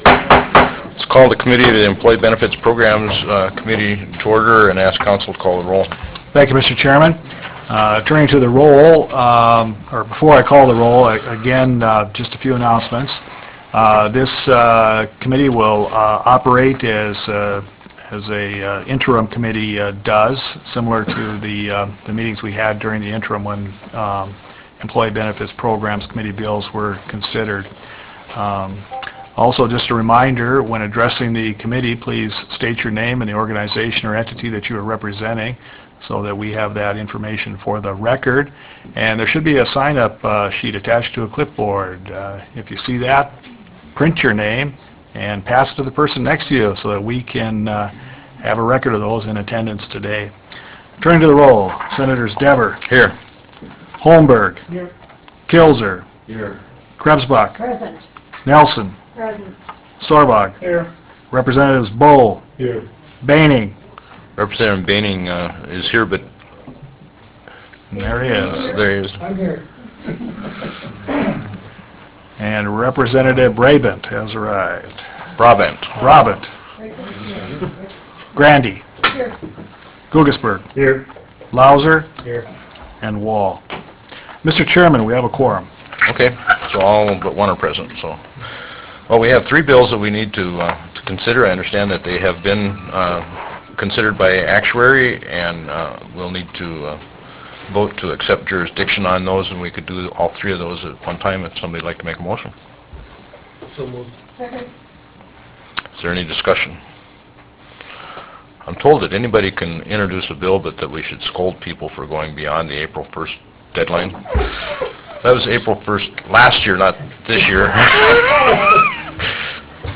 Missouri River Room State Capitol Bismarck, ND United States